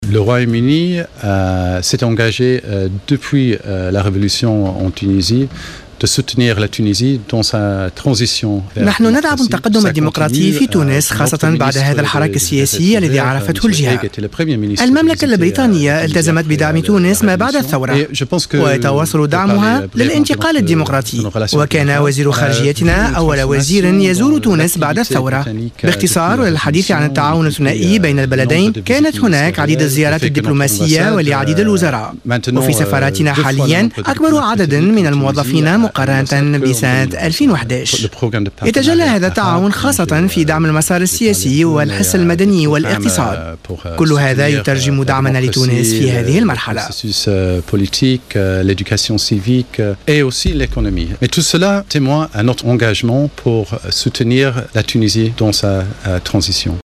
أكد سفير بريطانيا بتونس "أميش كووال" في حوار حصري مع جوهرة آف آم، ان بلاده كانت من أول الداعمين لثورة 14 جانفي 2011 مشددا على ضرورة انهاء المسار الانتقالي الحالي